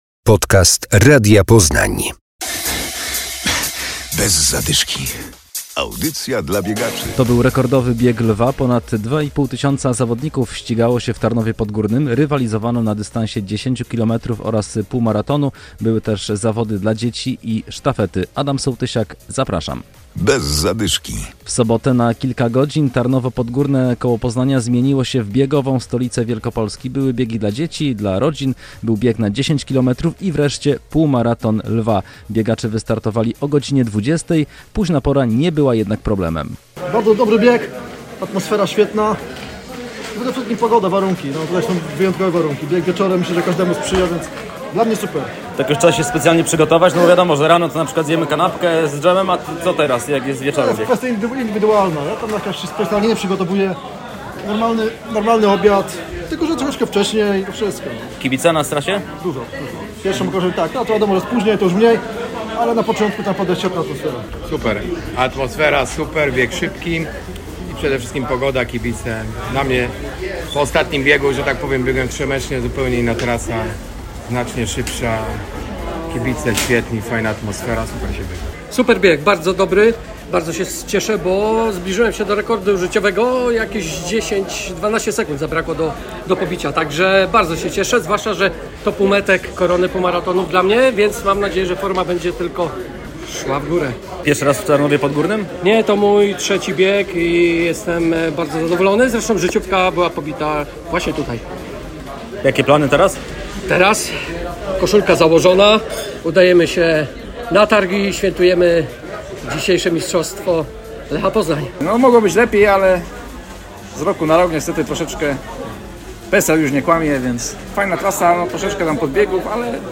Relacja z Biegu Lwa w Tarnowie Podgórnym.